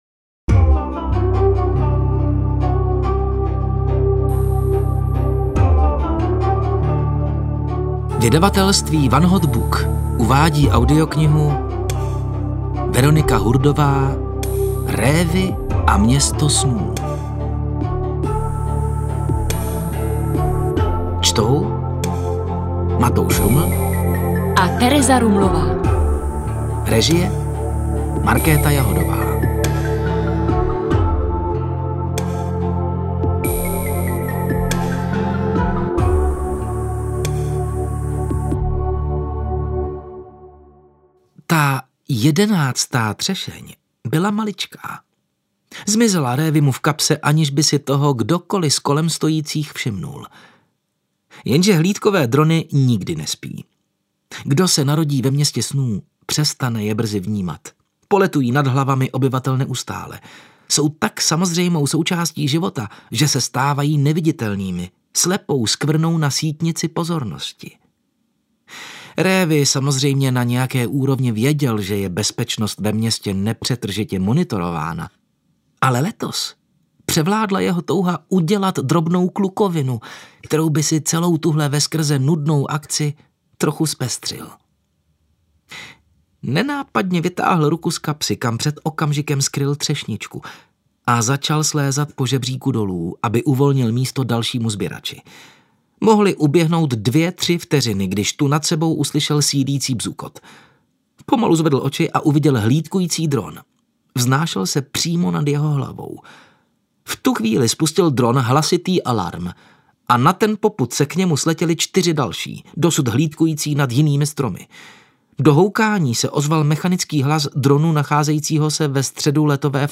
Révi a Město snů audiokniha
Ukázka z knihy